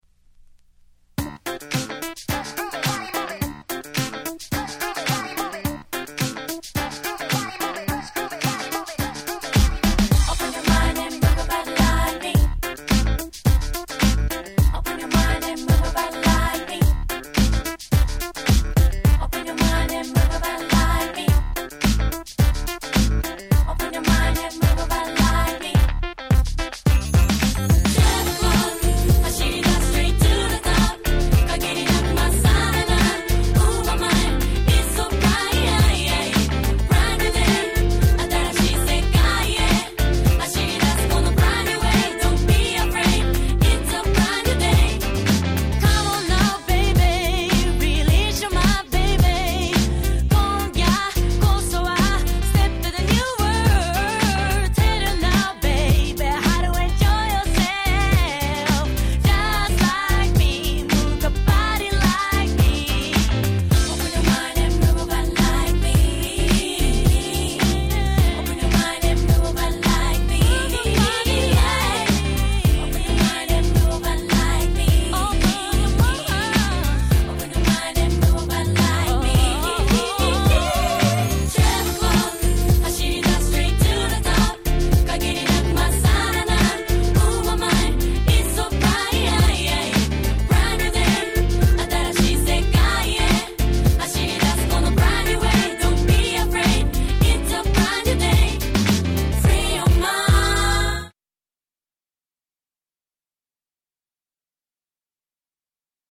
J-Pop畑でもそれなりに流行った姉妹ユニットのデビューシングル！
キャッチー系